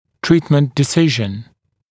[‘triːtmənt dɪ’sɪʒn][‘три:тмэнт ди’сижн]лечебное решение